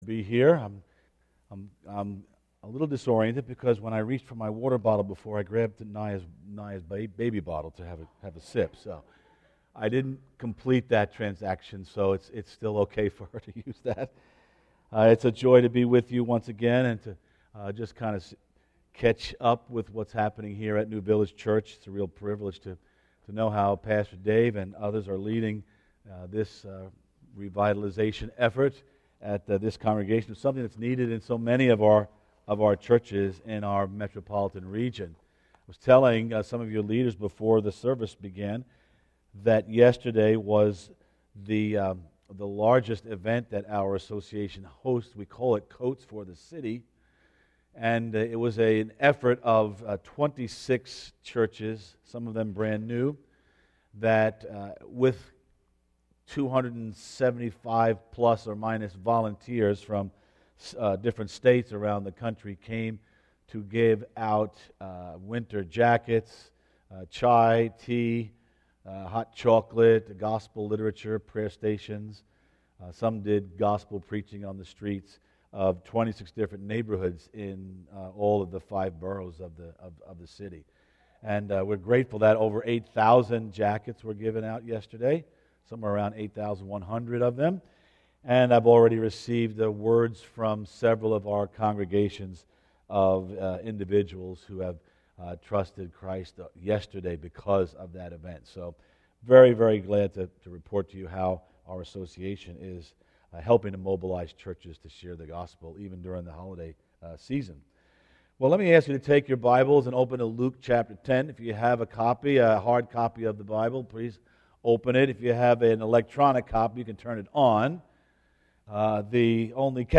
Sermons Archive - New Village Church